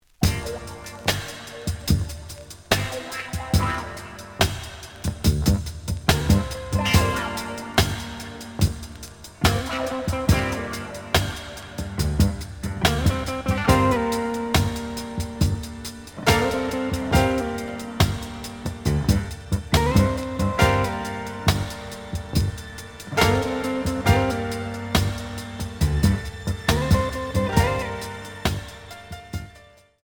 試聴は実際のレコードから録音しています。
●Format: 7 inch
●Genre: Disco